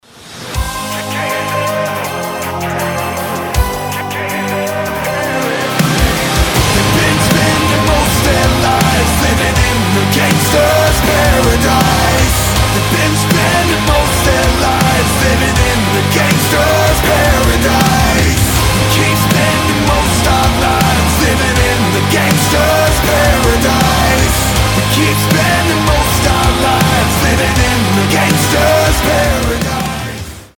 Разрывной метал кавер